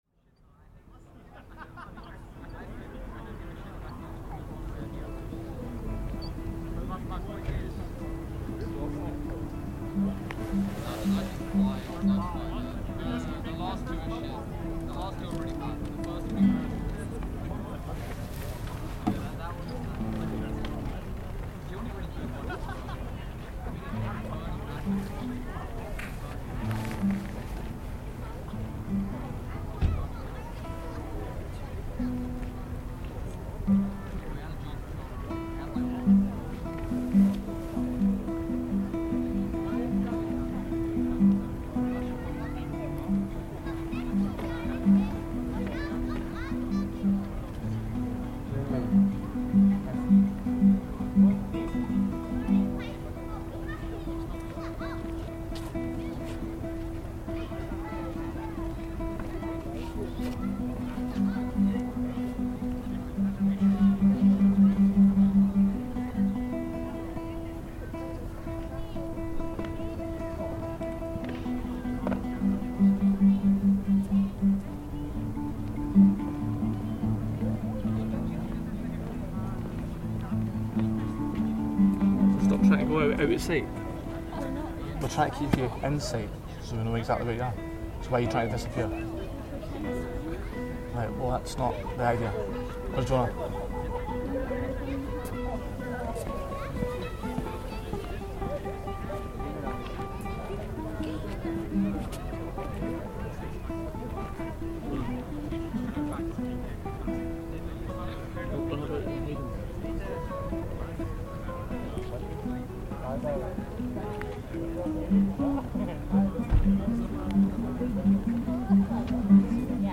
Sunset on Calton Hill, Edinburgh, Scotland - the sounds of walkers and a nearby guitar busker, summer 2021.